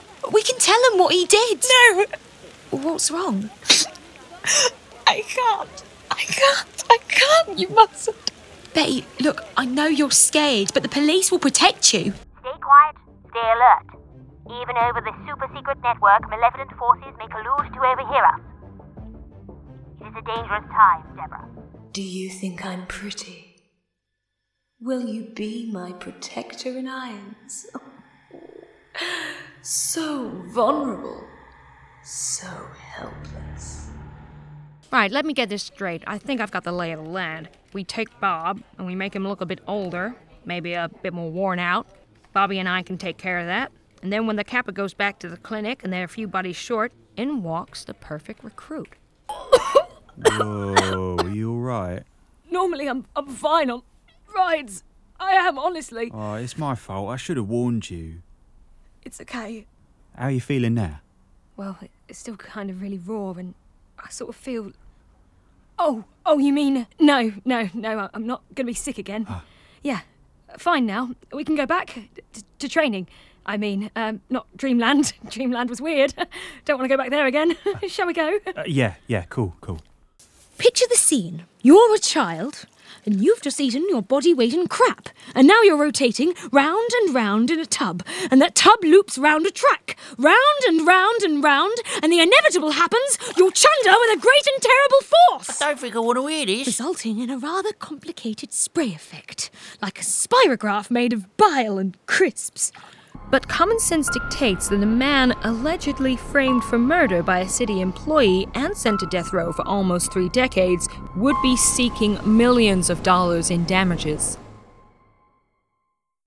Comedic, Laid Back
Gender Female
Accent Cockney Essex London Standard English R P
Audio Drama